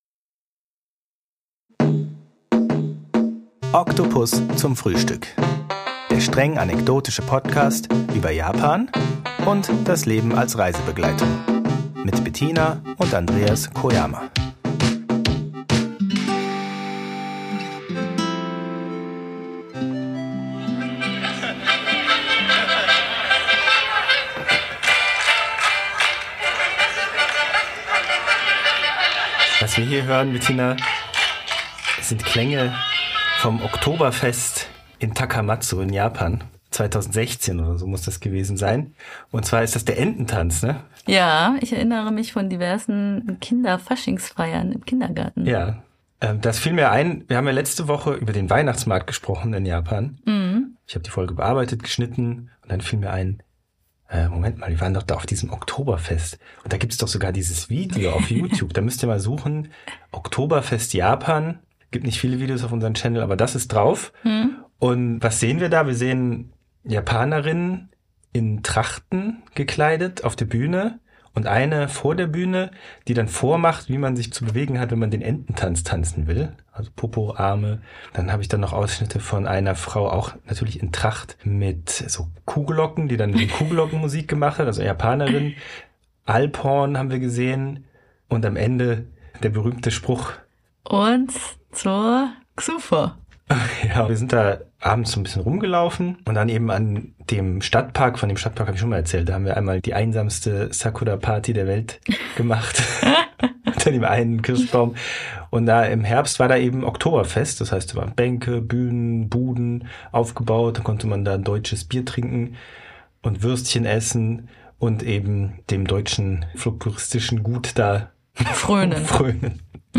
Zum Abschluss gibt es ein Ständchen im goldenen Rapsfeld vor Bergpanorama.